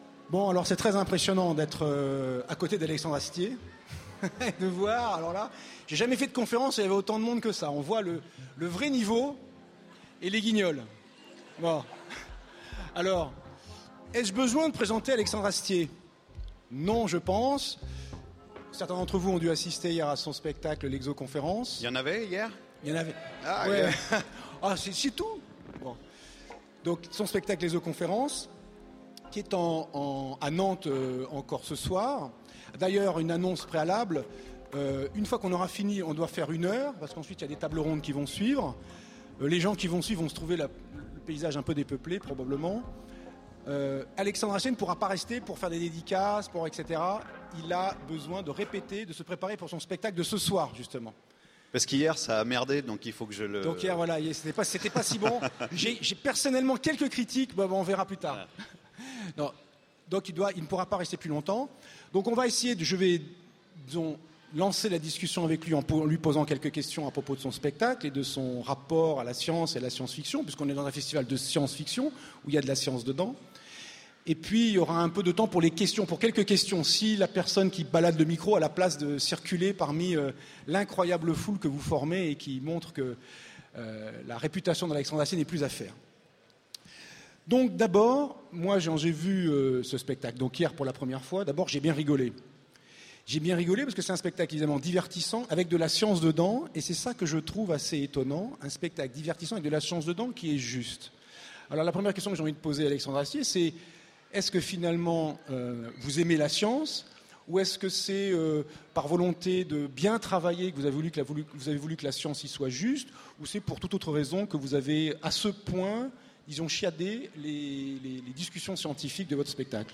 Utopiales 2014 : Rencontre avec Alexandre Astier
- le 31/10/2017 Partager Commenter Utopiales 2014 : Rencontre avec Alexandre Astier Télécharger le MP3 à lire aussi Alexandre Astier Genres / Mots-clés Rencontre avec un auteur Conférence Partager cet article